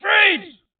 sfx_cop_freeze_4.mp3